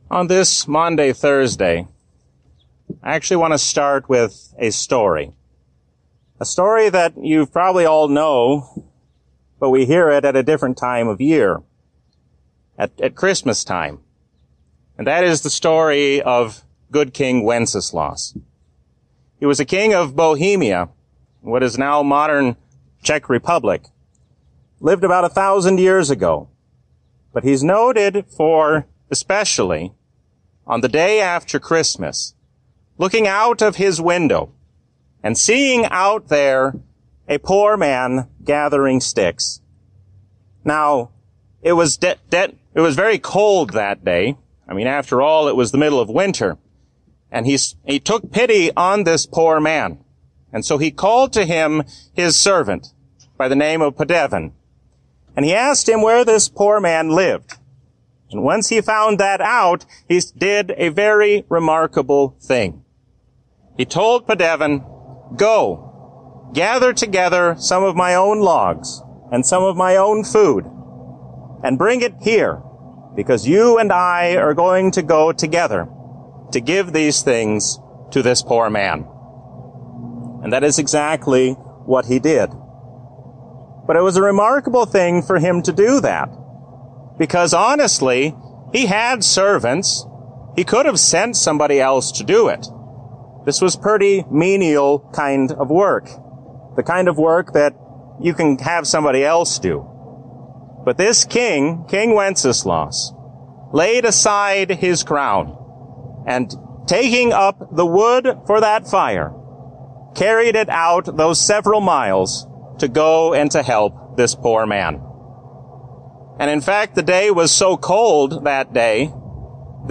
A sermon from the season "Trinity 2022." When we humble ourselves under the hand of God, then we have no reason to be anxious about the future.